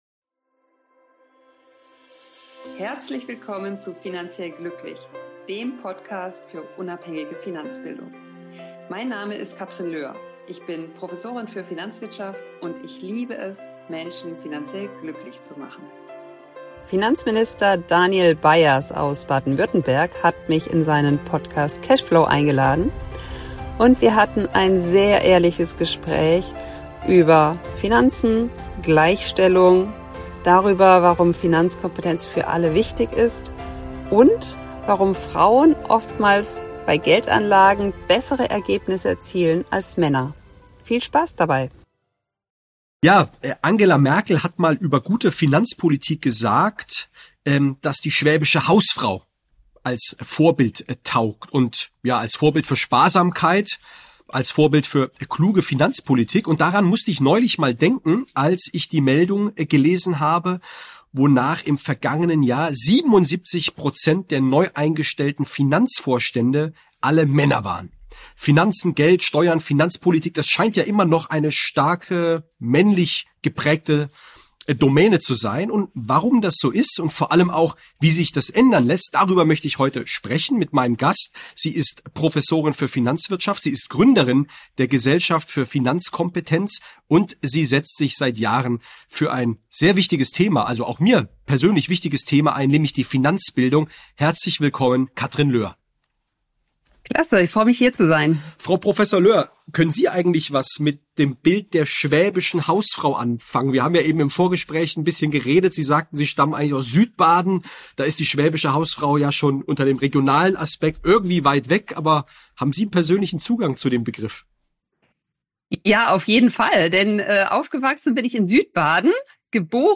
Im Gespräch mit Finanzminister Danyal Bayaz (BaWü) ~ Finanziell glücklich. Podcast